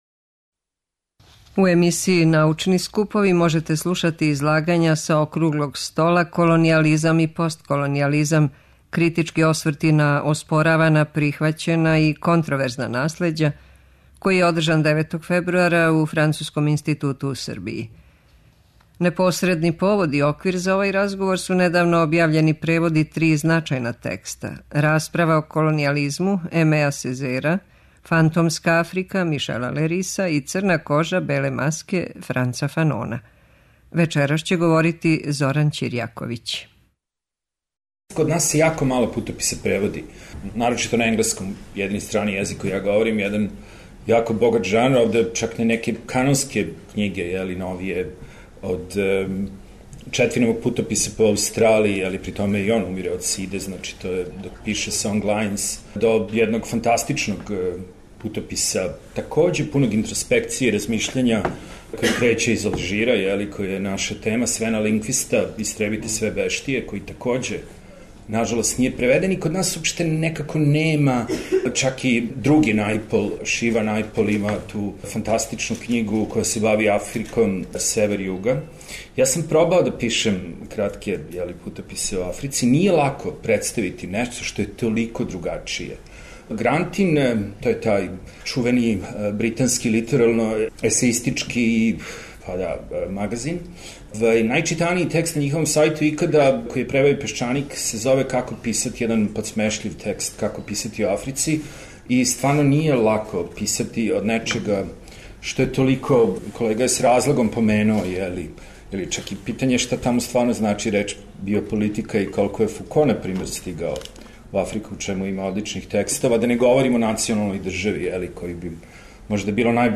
преузми : 7.37 MB Трибине и Научни скупови Autor: Редакција Преносимо излагања са научних конференција и трибина.